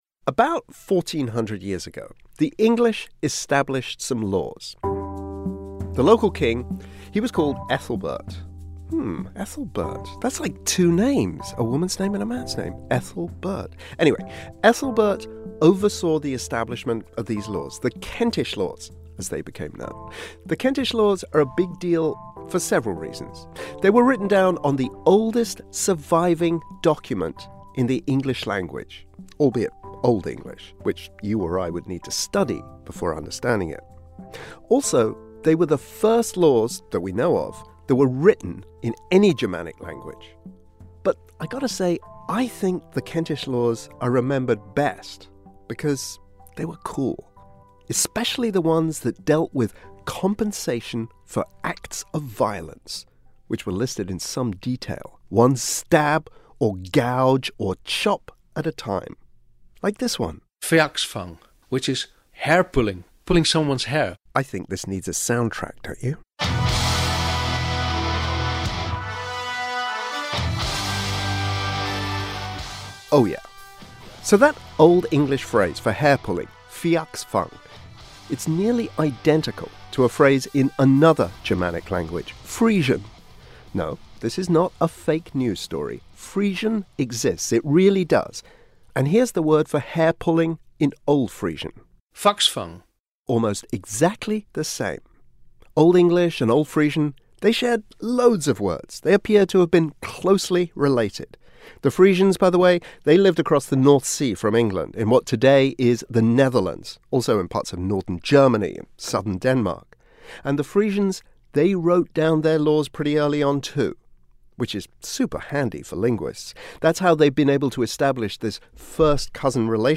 We head to the Netherlands to hear from artists, writers, politicians and kids at a trilingual school.